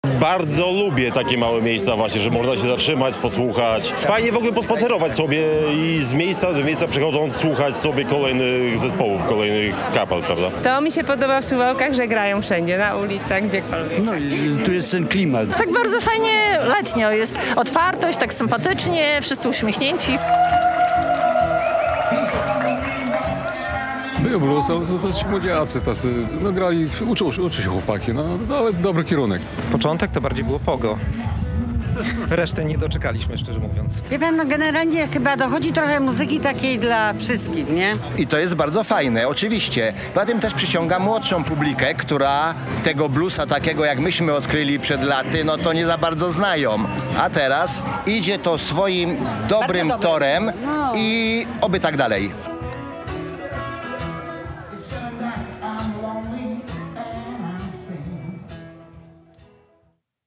Drugi dzień Suwałki Blues Festival - tradycyjnie rozpoczął się od śniadań bluesowych - relacja